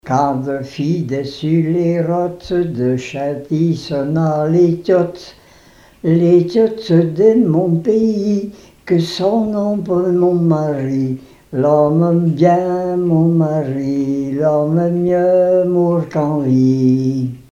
Mémoires et Patrimoines vivants - RaddO est une base de données d'archives iconographiques et sonores.
Genre laisse
chansons et témoignages parlés
Pièce musicale inédite